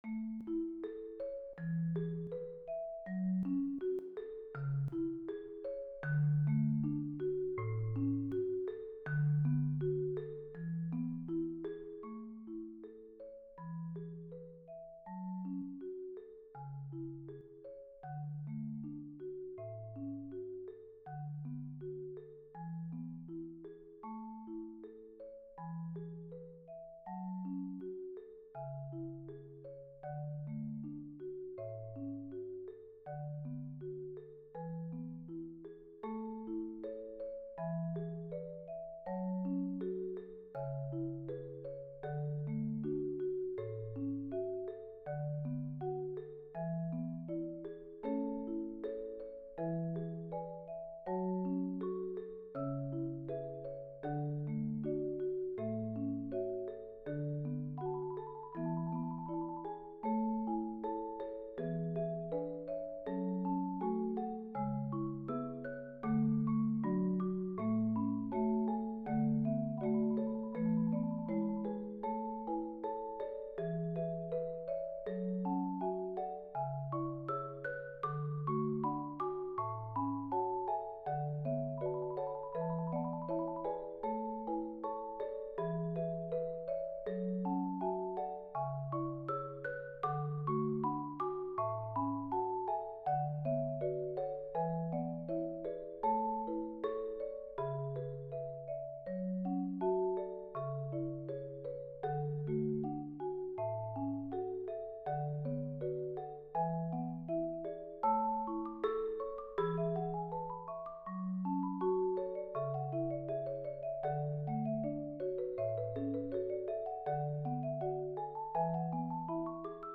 Voicing: Marimba Quintet